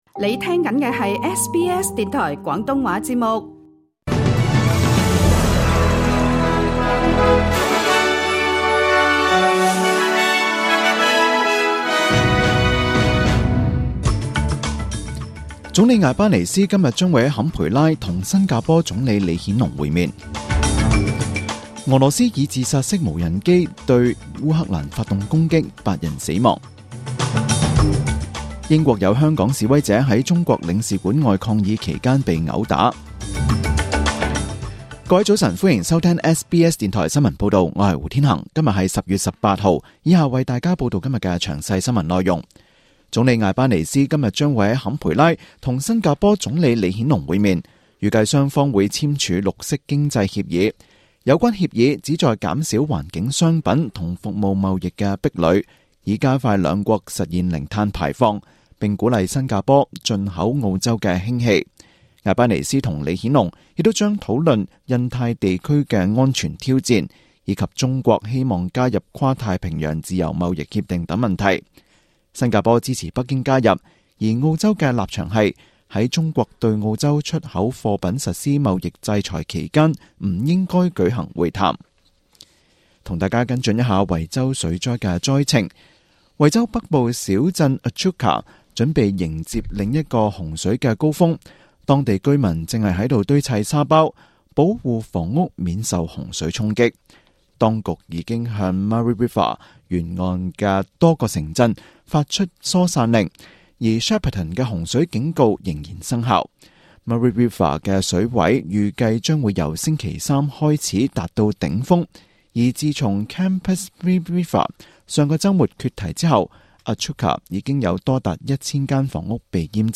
SBS廣東話節目中文新聞 Source: SBS / SBS Cantonese